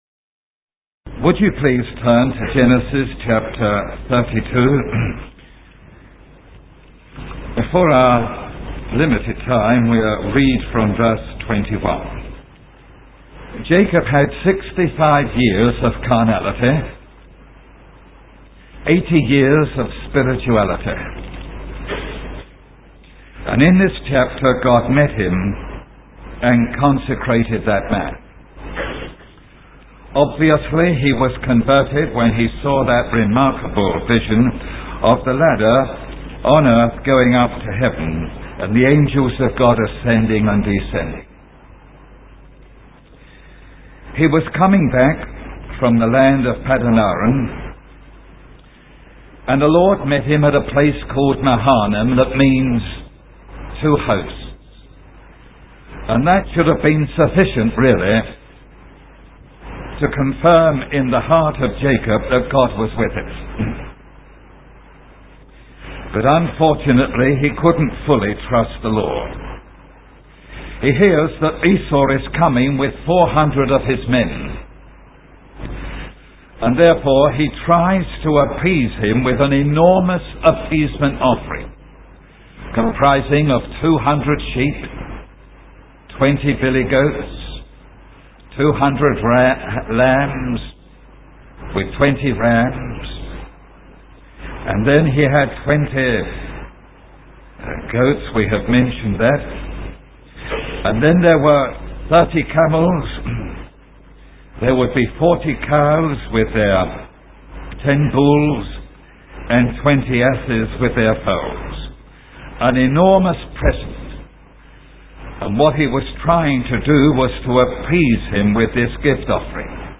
The sermon calls for individuals to confront their own fleshly resistance to God and to seek a personal encounter that leads to genuine transformation.